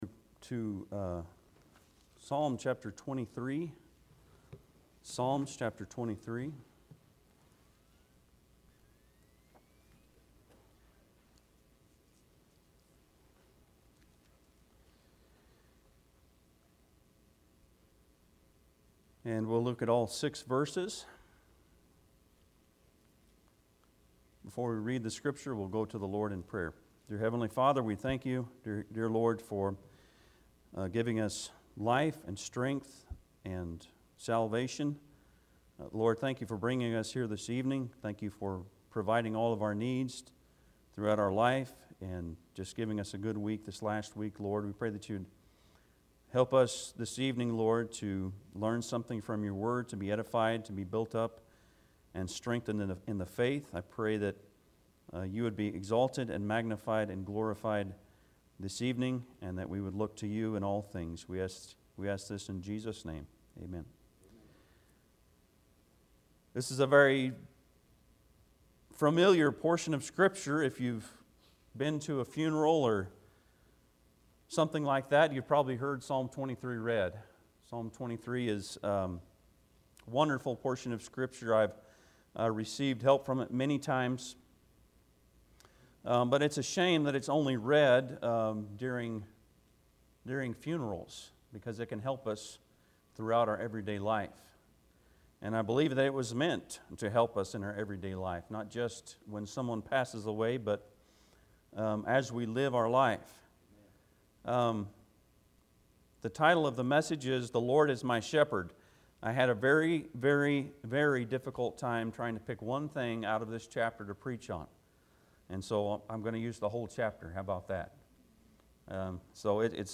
Psalms 23:1-6 Service Type: Sunday pm Bible Text